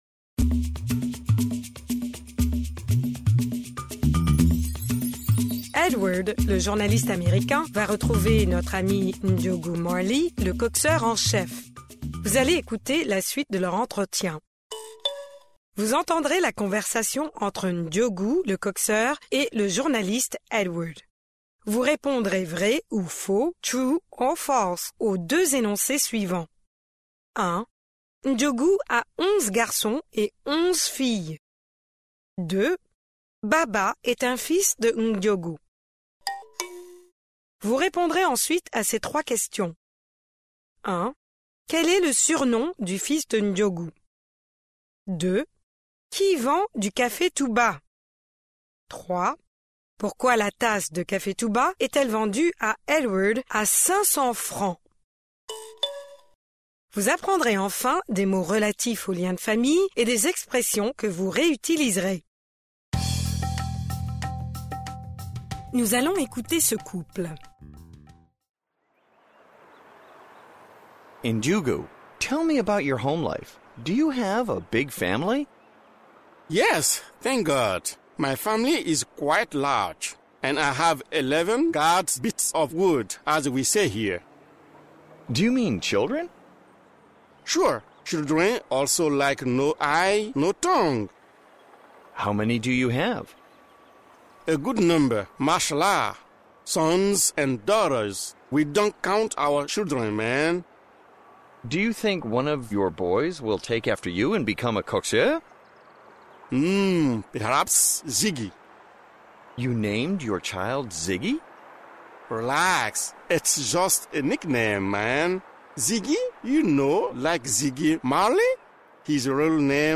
Vous allez écouter la suite de leur entretien.